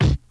Kick (Dusty-2).wav